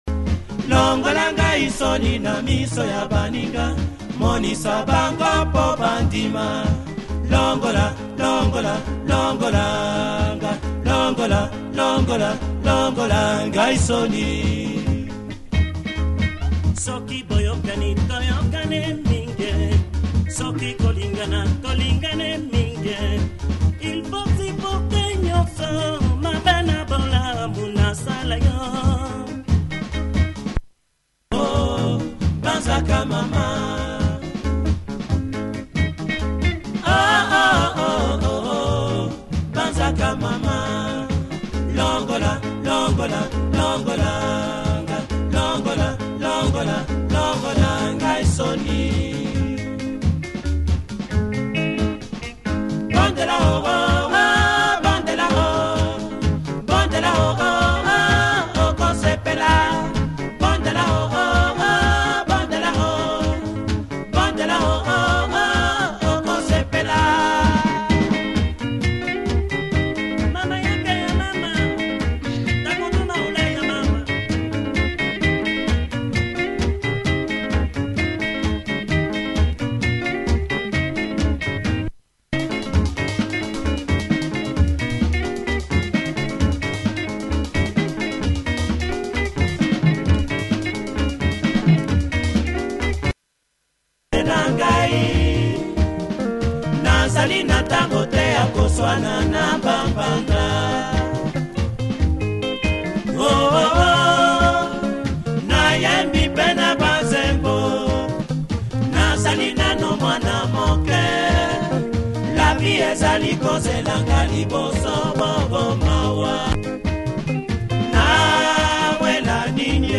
great drums and progressive beat